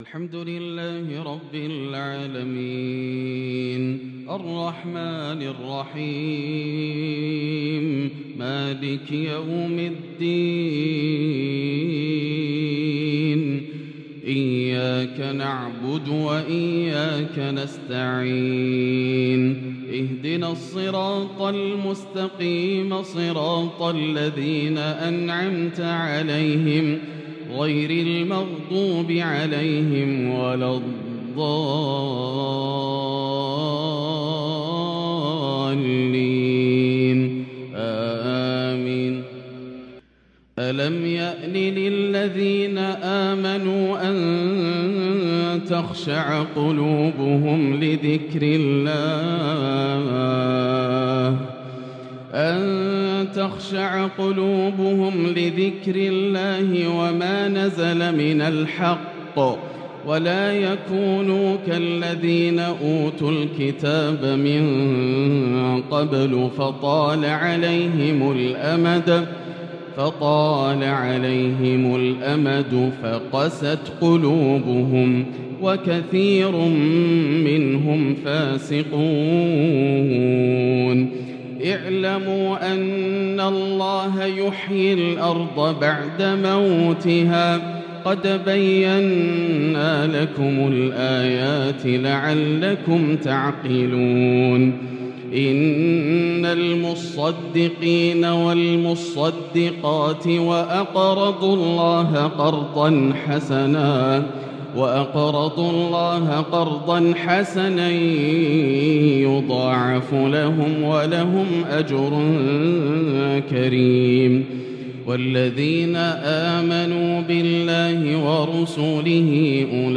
عشاء الأحد 5-7-1443هـ من سورة الحديد | Isha prayer from Surat Al-Hadid 6-2-2022 > 1443 🕋 > الفروض - تلاوات الحرمين